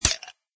icemetal3.mp3